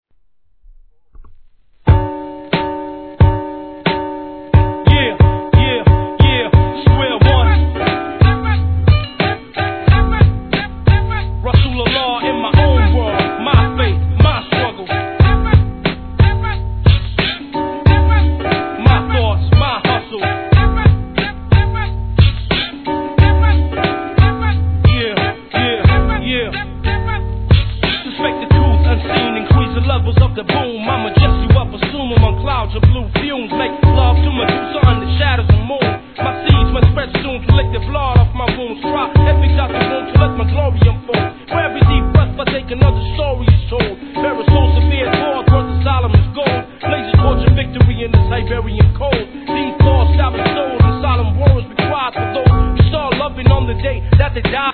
HIP HOP/R&B
ドイツ産アンダーグランド